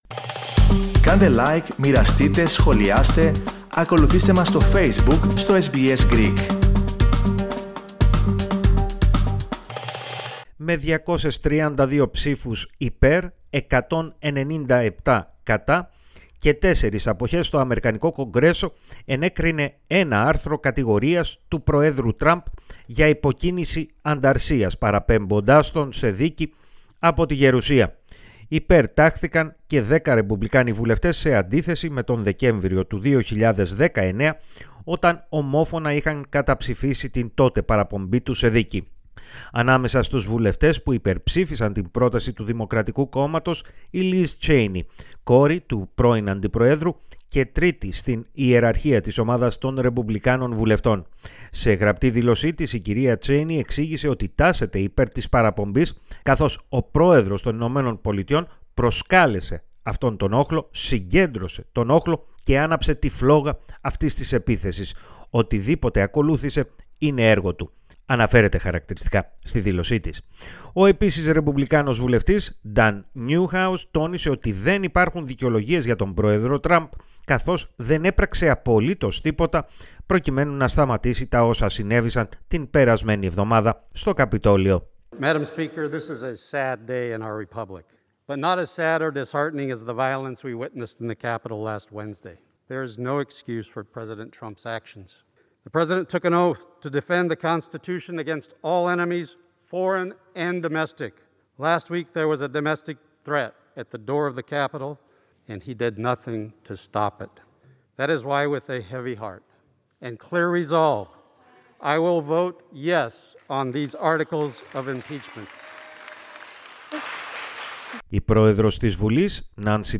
Στις μελανές σελίδες της ιστορίας καταγράφεται, ο Ντόναλντ Τραμπ, ως ο πρώτος πρόεδρος των ΗΠΑ, που παραπέμπεται, δύο φορές, από τη Βουλή των Αντιπροσώπων, σε δίκη από τη Γερουσία. Περισσότερα ακούαστε στην αναφορά